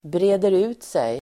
Uttal: [bre:der'u:tsej]